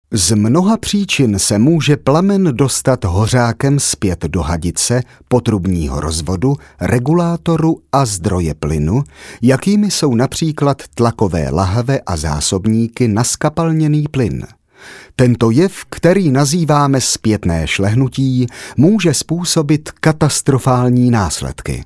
Sprecher tschechisch fĂŒr TV / Rundfunk / Industrie.
Kein Dialekt
Sprechprobe: Industrie (Muttersprache):
Professionell voice over artist from Czech.